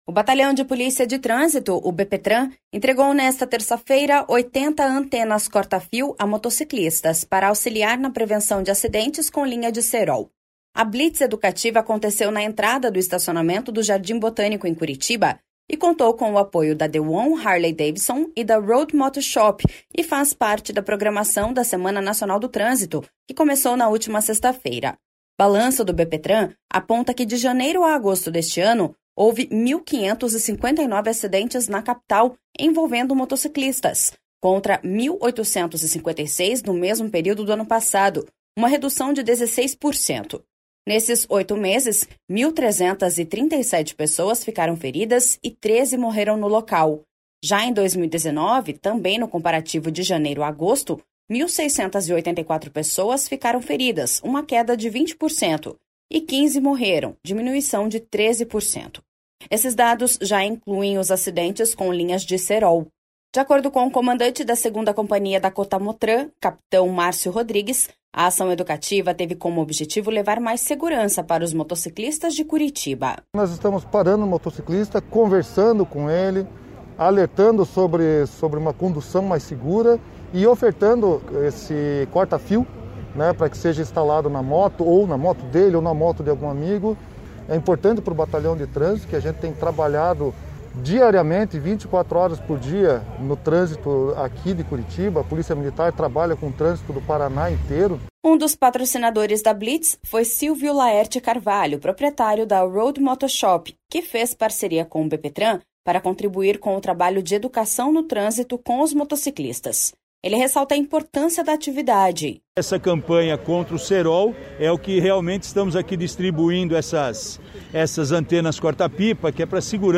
O Batalhão de Polícia de Trânsito, BPTran, entregou nesta terça-feira 80 antenas corta-fio a motociclistas para auxiliar na prevenção de acidentes com linha de cerol. A blitz educativa aconteceu na entrada do estacionamento do Jardim Botânico em Curitiba, e contou com apoio da The One Harley Davidson e da Road Moto Shop, e faz parte da programação da Semana Nacional do Trânsito, que começou na última sexta-feira.